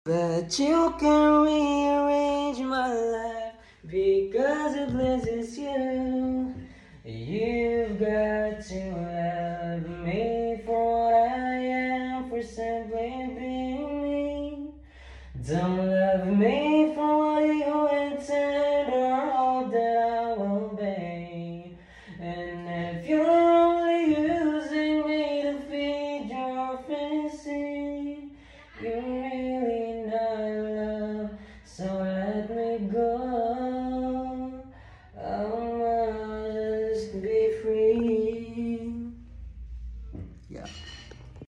Try ko lang mag acapella